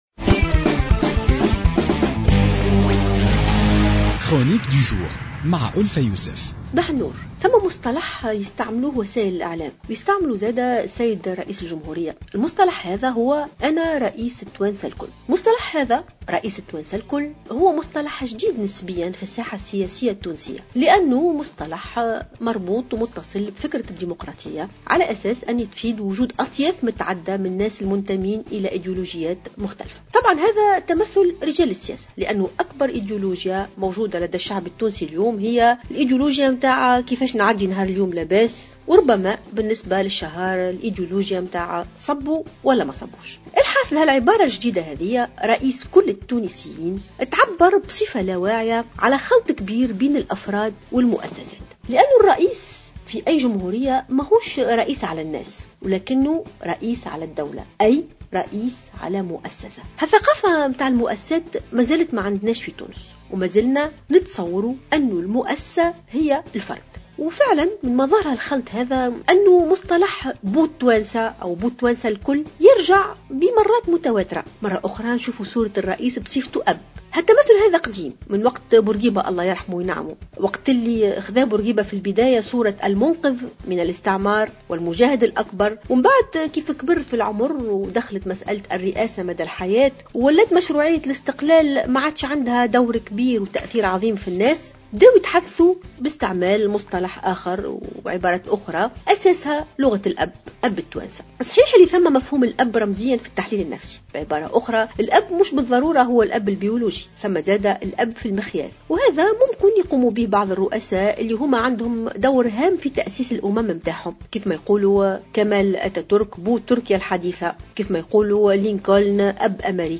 قالت الباحثة والأستاذة الجامعية ألفة يوسف في افتتاحية اليوم الاثنين 08 فيفري 2016 إن ثقافة المؤسسات مازالت لم تتأصل بعد في تونس مشيرة إلى أن هناك خلطا كبيرا في المفاهيم بين الفرد والمؤسسة.